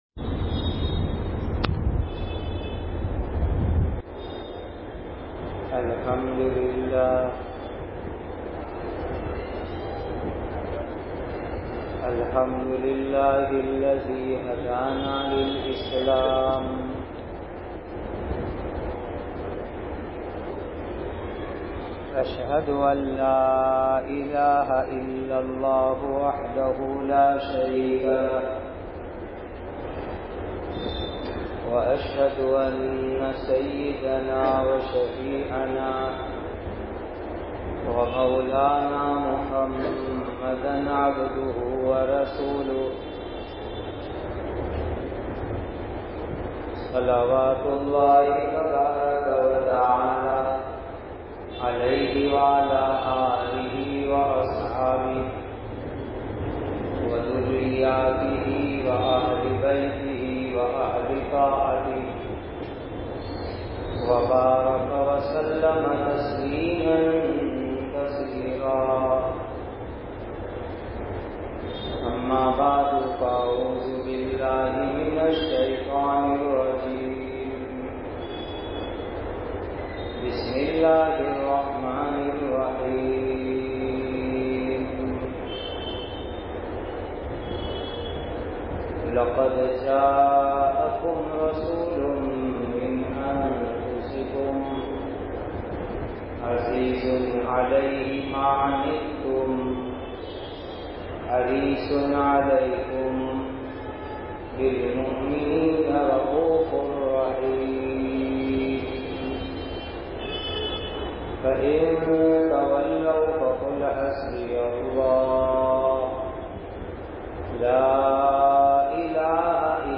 Hz. Haji Imdadullah Sb. Muhajir E Makki Rh. Ke Halaat, Part 3, Jumuah Bayan, Masjid E Darussalam, Godhra Explained by